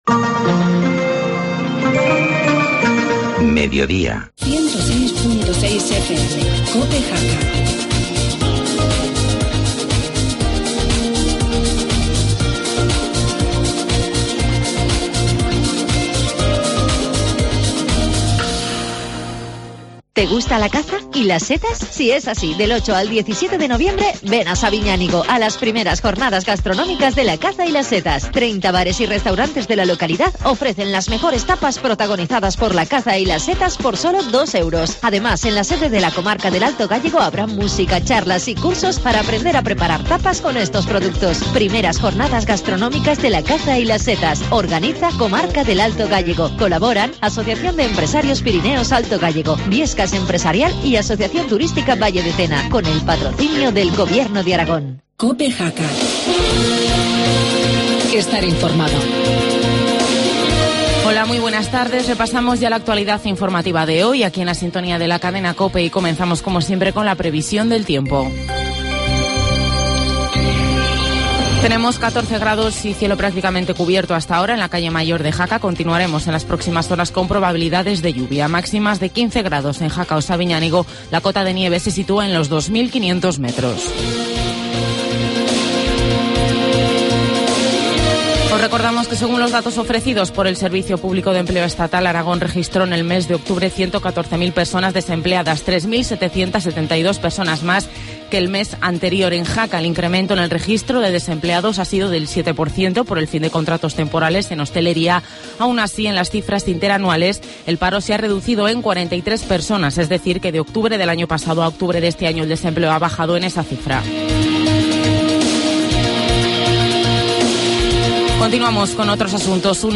Informativo mediodía, martes 5 de noviembre